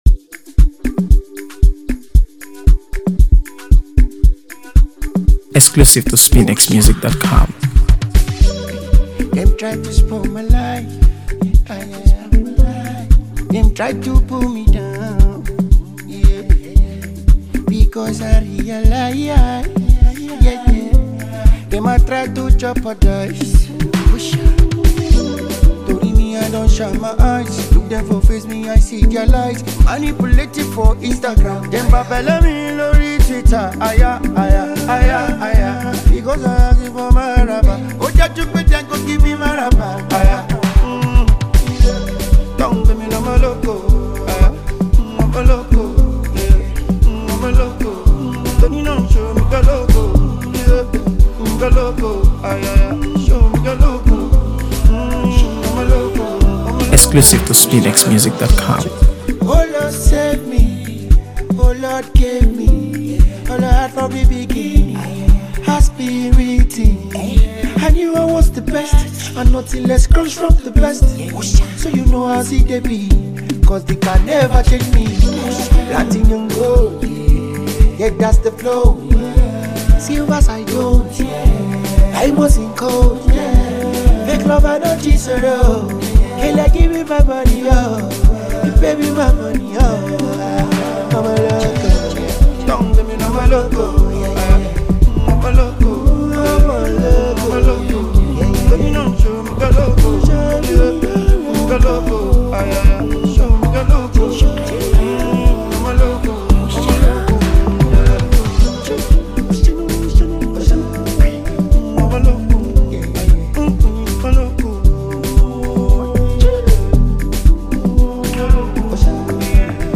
AfroBeats | AfroBeats songs
Packed with catchy lyrics and an infectious Afrobeat rhythm